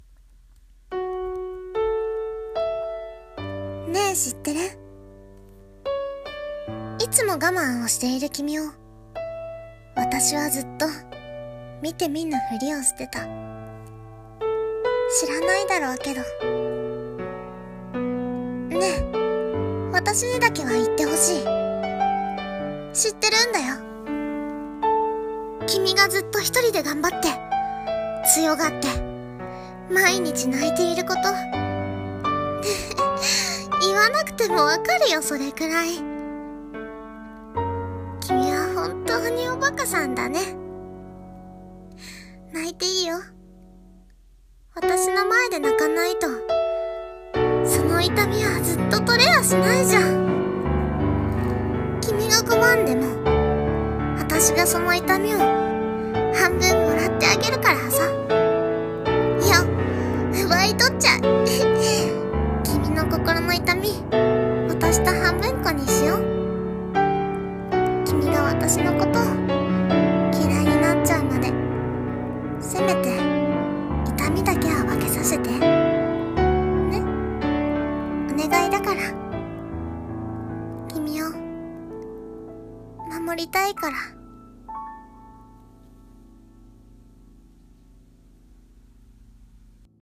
【一人声劇台詞】痛みを半分に